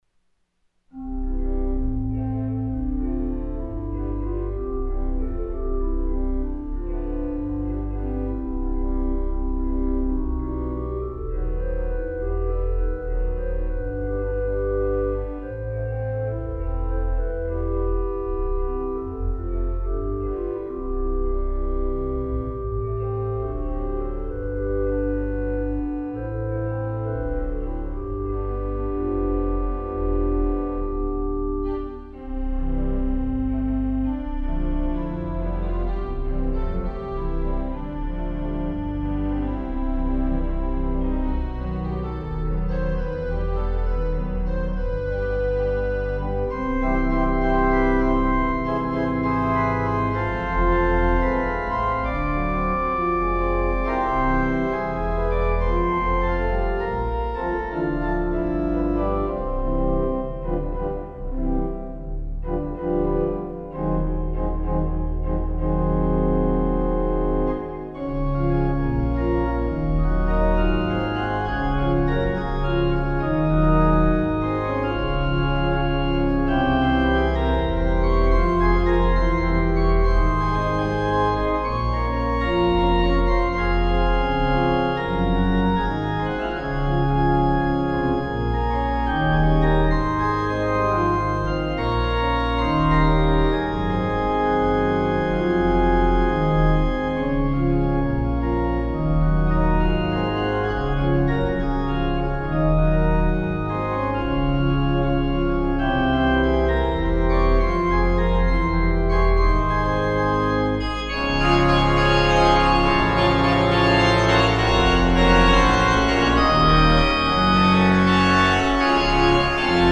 Here is the baldwin with me at the wheel
one take late at night as an ebay demo file.